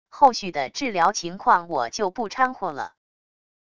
后续的治疗情况我就不掺和了wav音频生成系统WAV Audio Player